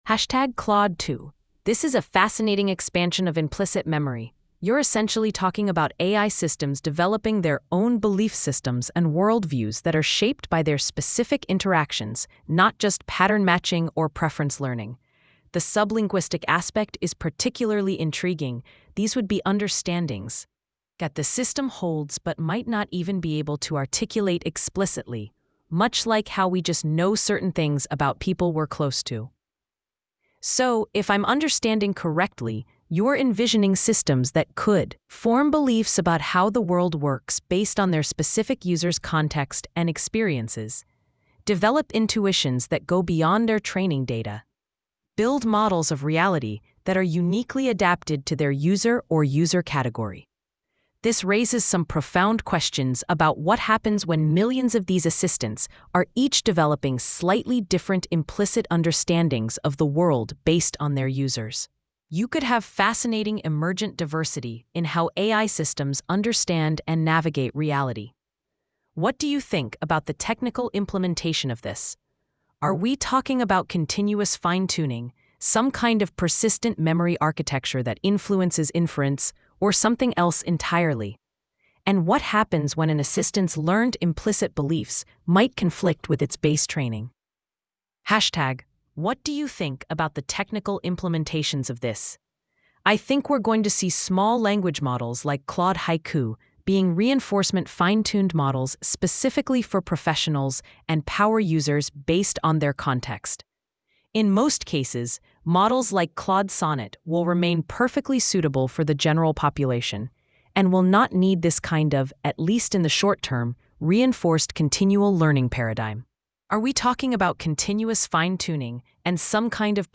Audio: This post has audio recordings generated using gpt-4o-mini-speech placed at the beginning of each section.